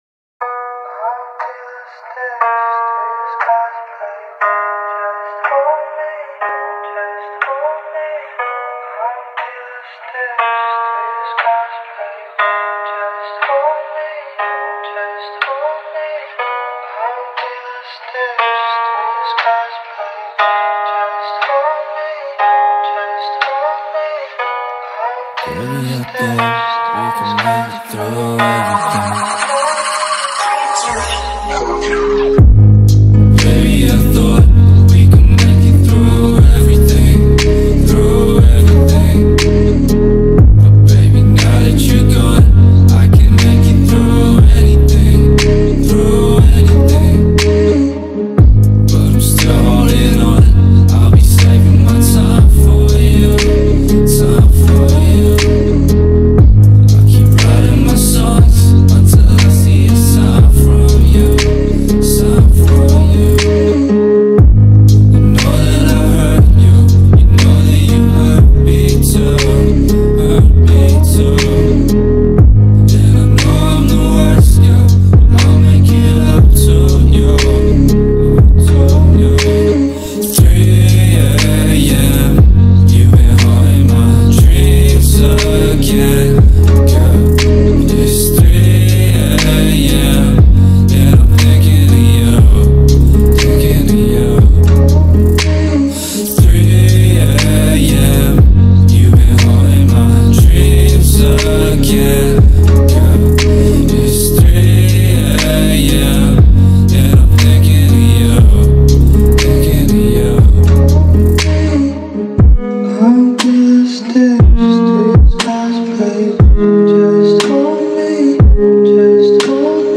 نسخه تقویت بیس قوی سیستمی